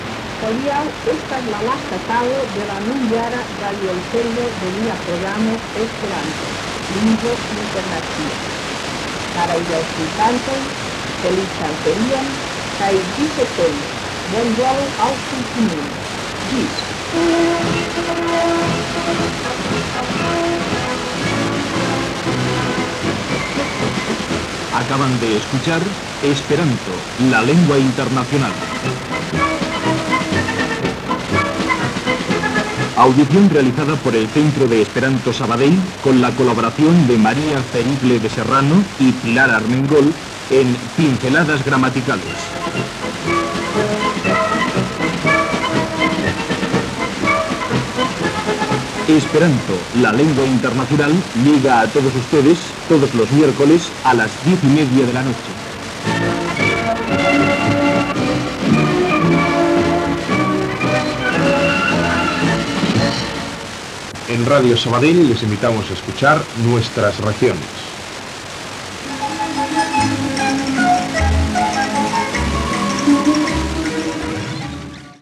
Comiat del programa en esperanto i careta de sortida en castellà.
Qualitat inicial de l'àudio deficient.